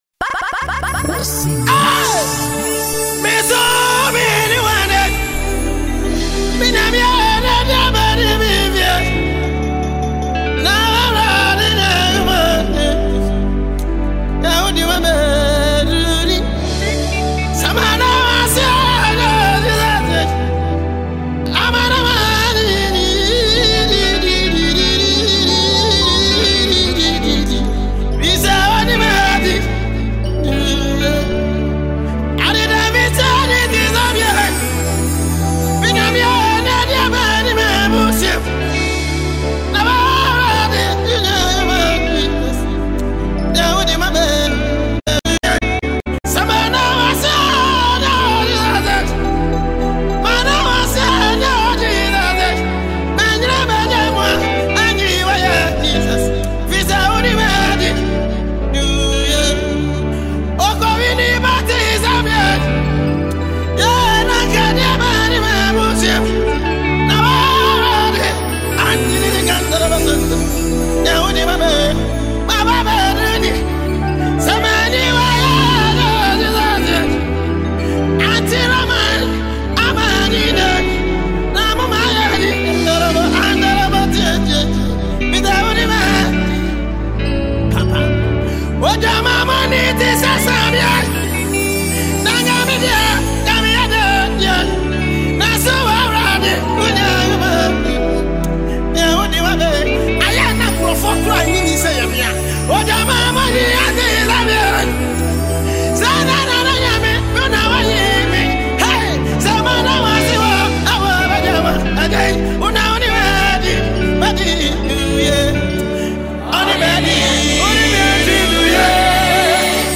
commanding vocals